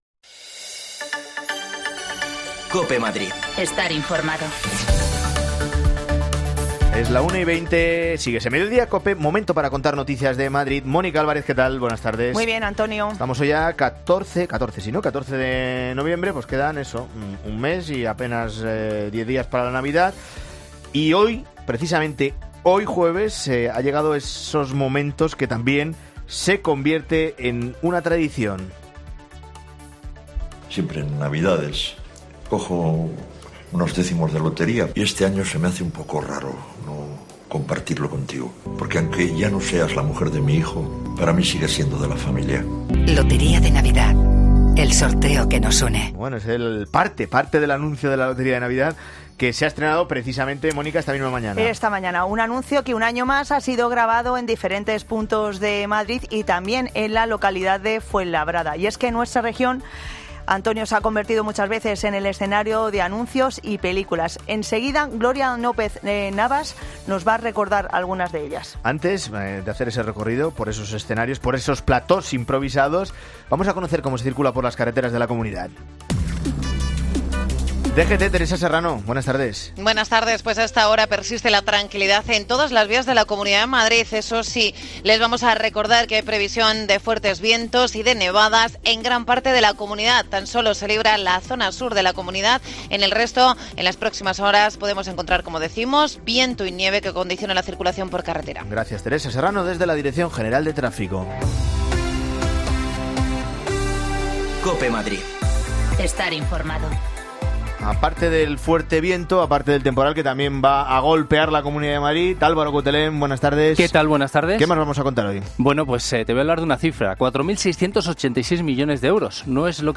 Escucha ya las desconexiones locales de Madrid de Herrera en COPE en Madrid y Mediodía COPE en Madrid.
Te contamos las últimas noticias de la Comunidad de Madrid con los mejores reportajes que más te interesan y las mejores entrevistas, siempre pensando en el ciudadano madrileño.